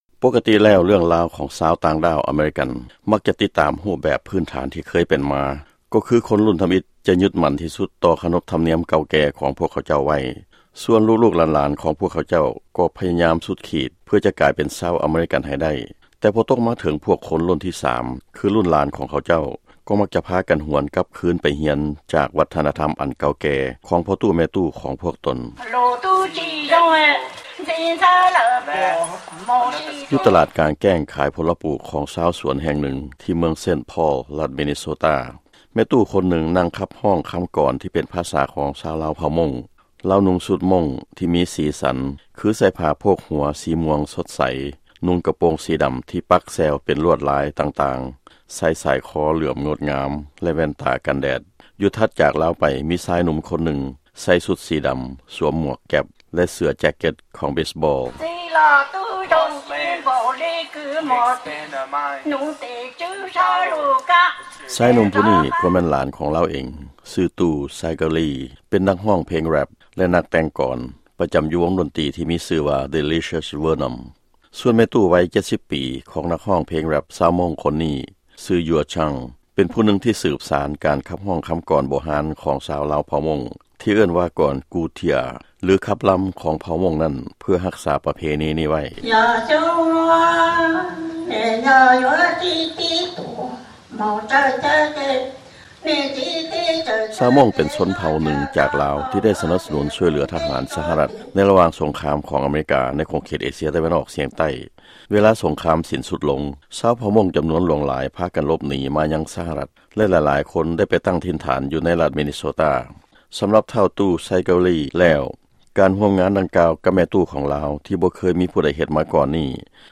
ຟັງລາຍງານ ເພງ Rap ແລະຂັບກອນ ກູເທຍອາ ຂອງຊາວເຜົ່າມົ້ງ